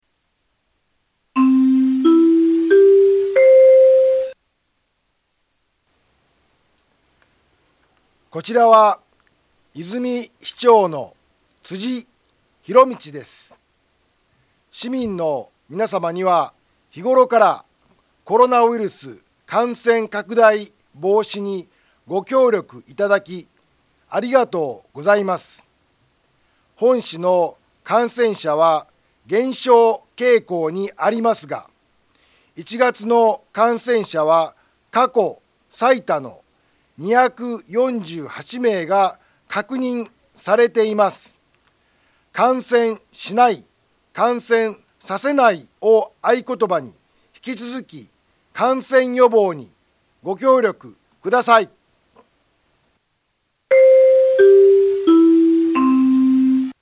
Back Home 災害情報 音声放送 再生 災害情報 カテゴリ：通常放送 住所：大阪府和泉市府中町２丁目７−５ インフォメーション：こちらは、和泉市長の辻 ひろみちです。